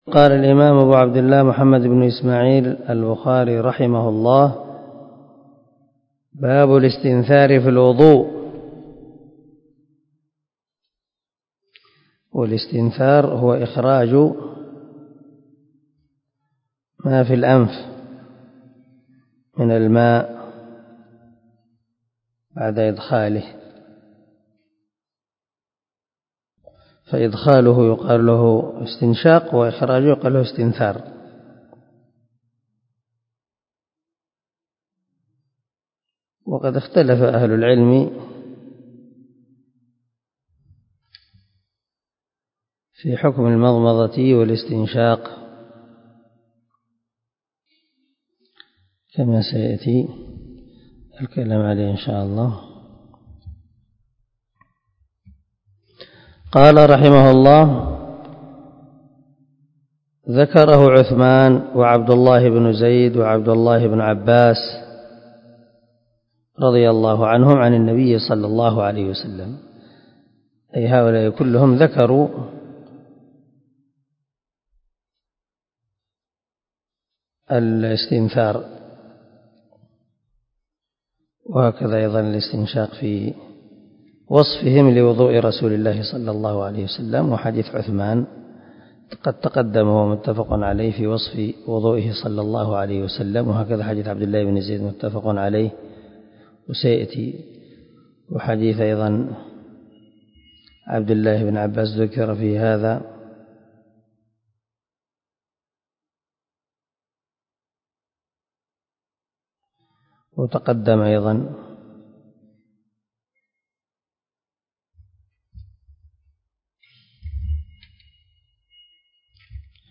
146الدرس 22 من شرح كتاب الوضوء حديث رقم ( 161 ) من صحيح البخاري
دار الحديث- المَحاوِلة- الصبيحة.